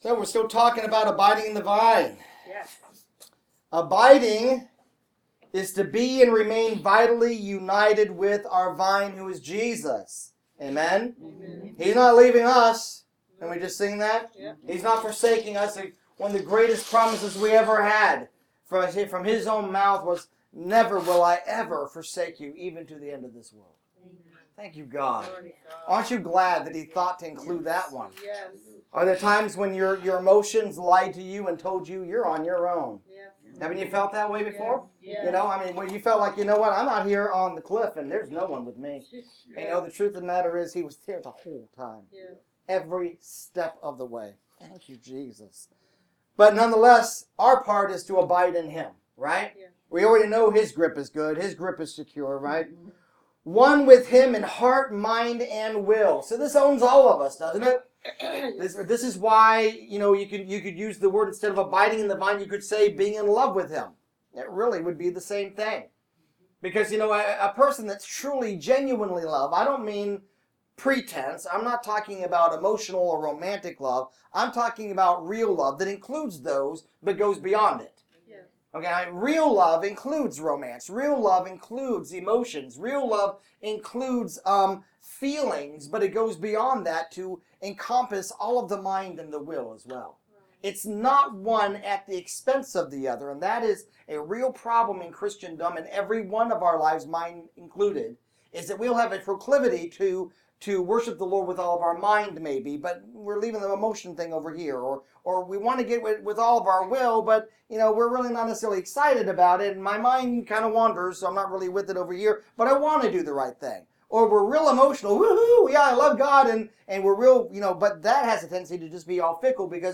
Wednesday 06/24/15 Series: Abiding in the Vine VI Message – Abiding in the Vine VI.mp3 Click here for the Video Abiding in the Vine…